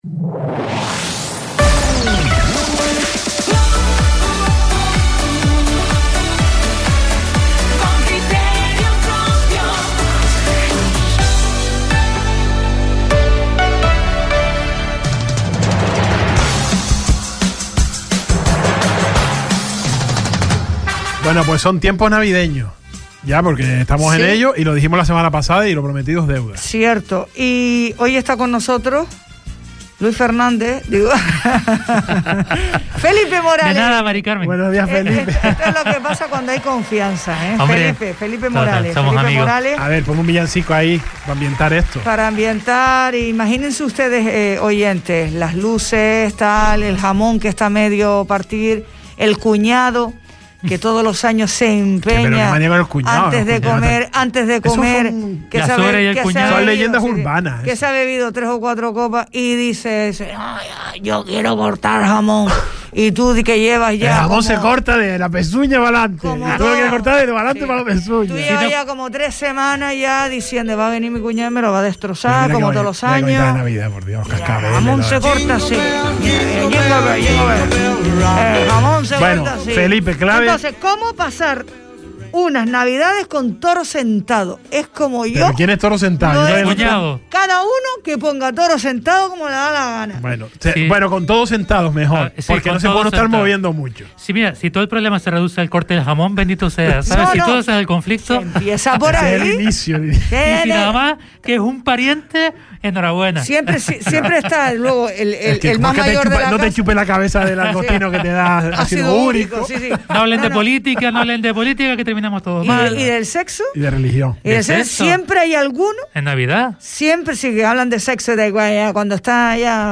El Salpicón | Entrevista